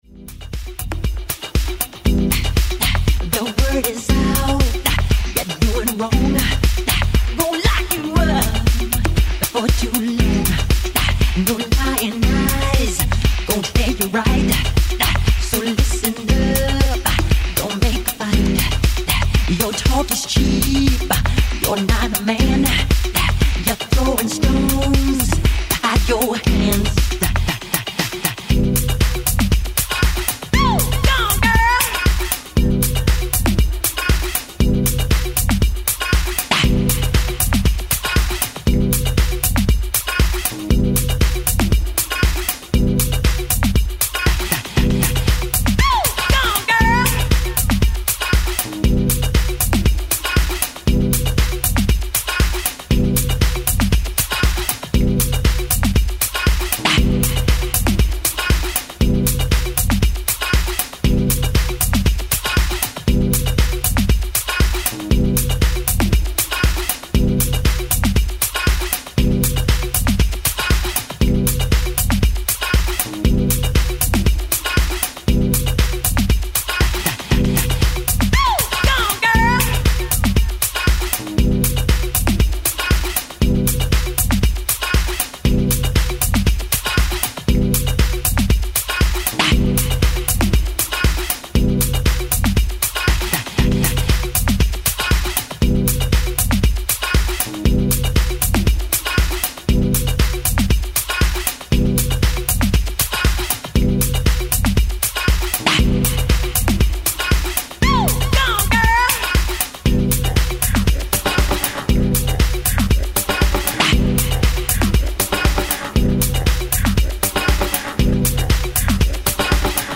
HOUSE/BROKEN BEAT